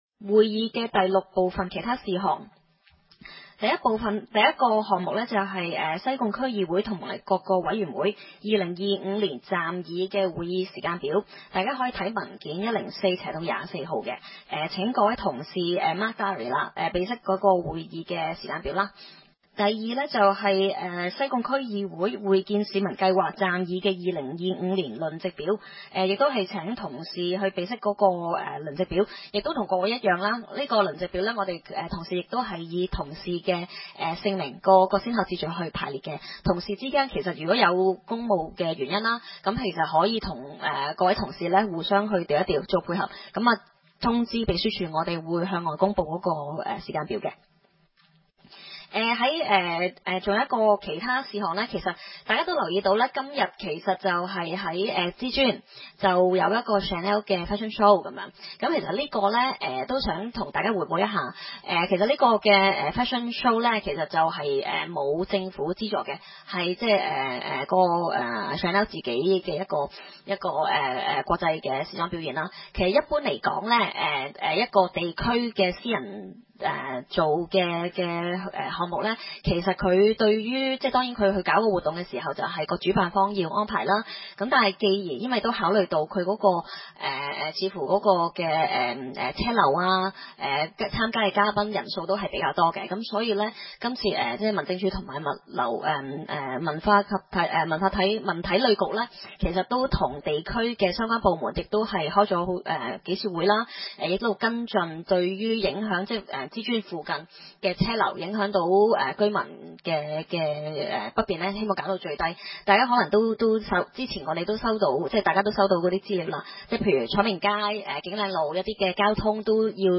區議會大會的錄音記錄
西貢將軍澳政府綜合大樓三樓